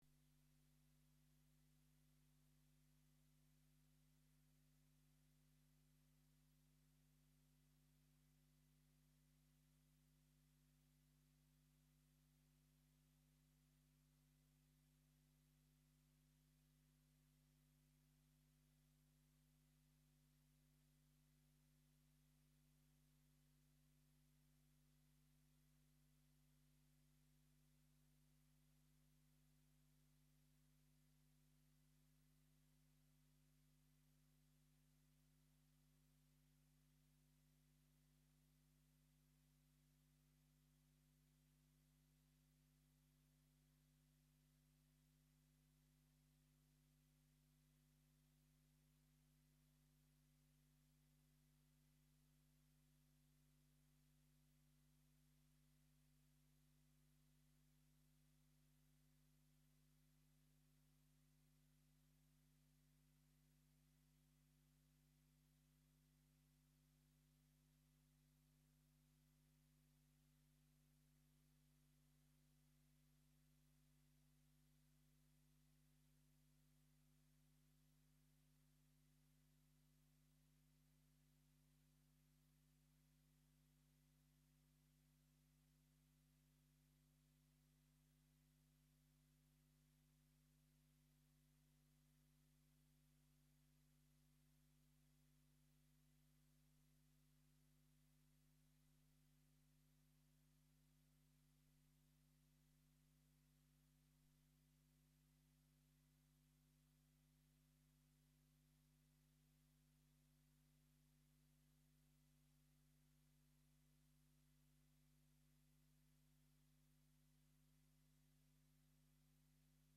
Gent de la Casa Gran (1977-1983). I Cicle de conferències